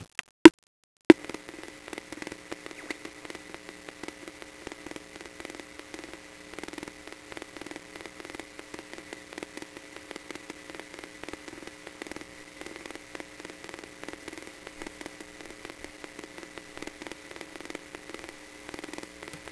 more morse
The resulting clicks are audible over the speaker noise but are not well-suited for more complicated projects.
morse.wav